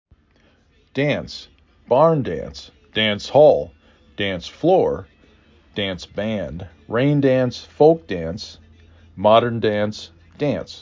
5 Letters, 1 Syllable
d a n s